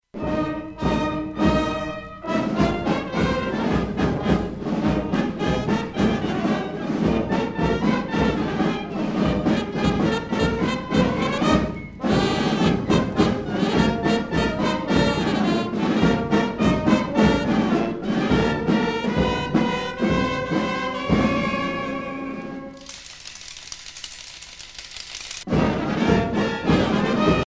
Fonction d'après l'analyste gestuel : à marcher
Pièce musicale éditée